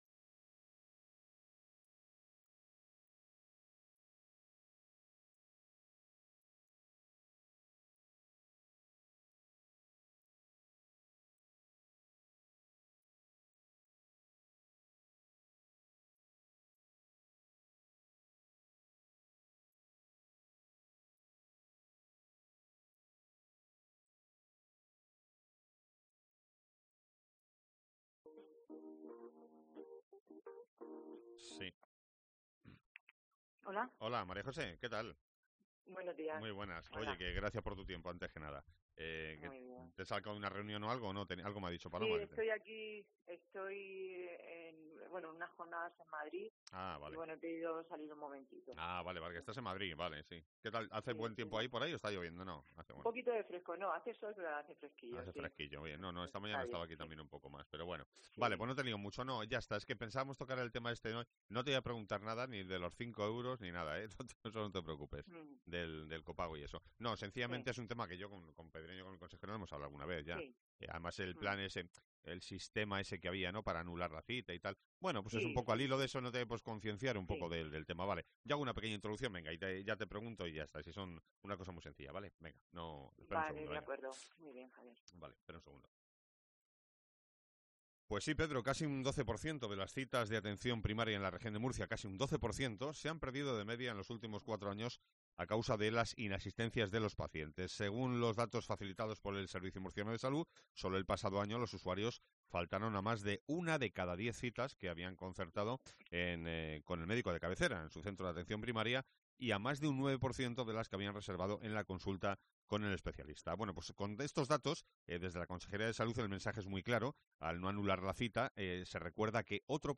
María José Marín, directora general de Atención Primaria